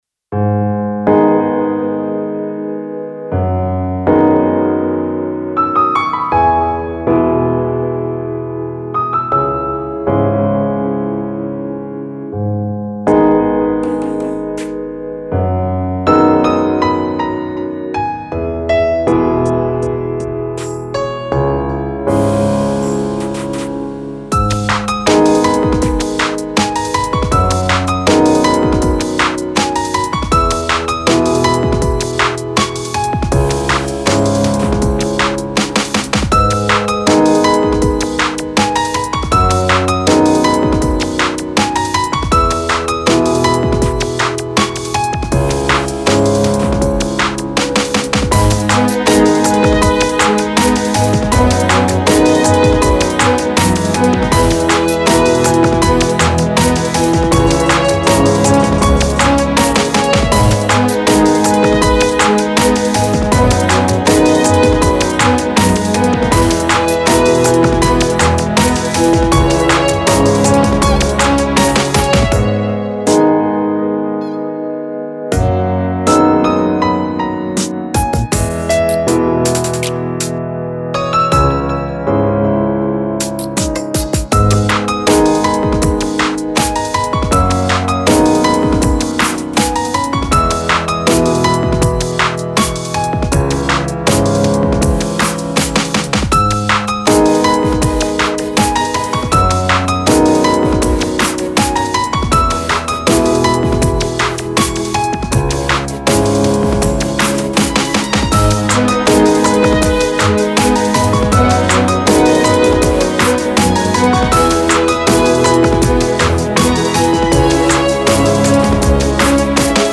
a pensive track with sad notes and a trip trop beat.
emotional
dreamy
sad
ambient
instrumental
dramatic
strings
orchestra